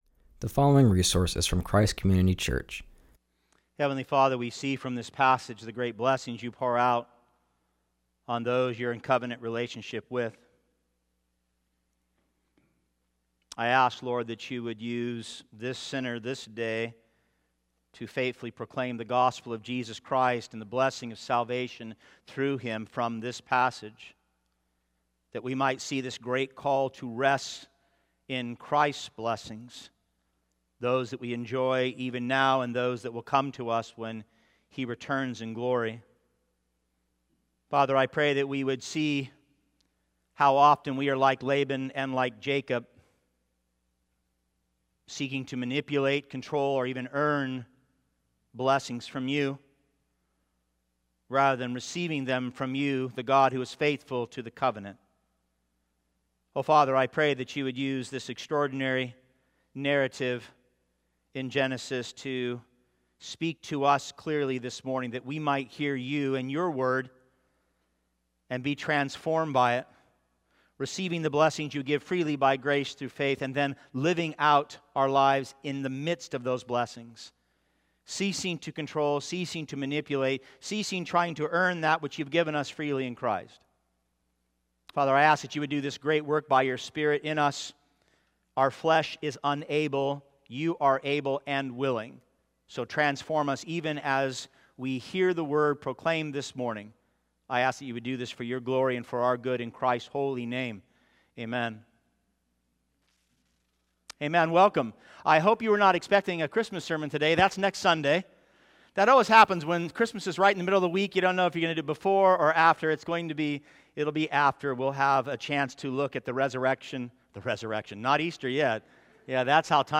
continues our series and preaches from Genesis 30:25-31:16.